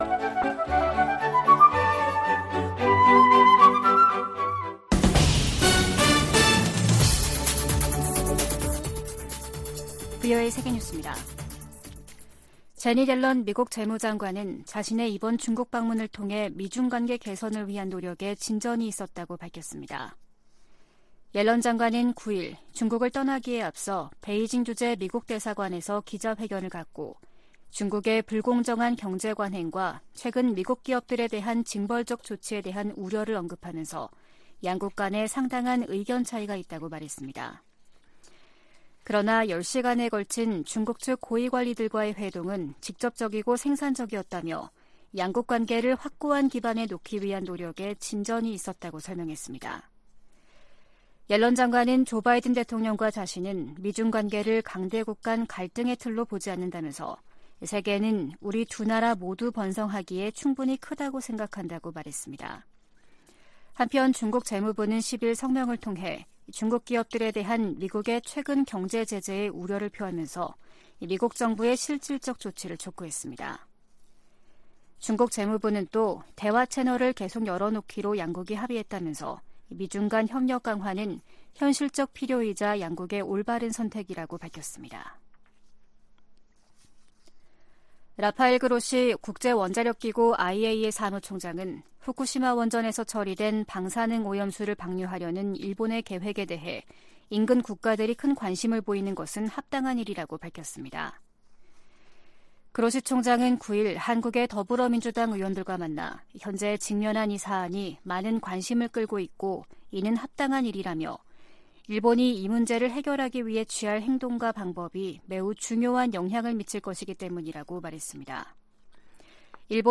VOA 한국어 아침 뉴스 프로그램 '워싱턴 뉴스 광장' 2023년 7월 11일 방송입니다. 미국과 한국이 오는 18일 서울에서 핵협의그룹 출범회의를 엽니다.